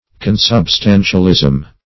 Search Result for " consubstantialism" : The Collaborative International Dictionary of English v.0.48: Consubstantialism \Con`sub*stan"tial*ism\, n. The doctrine of consubstantiation.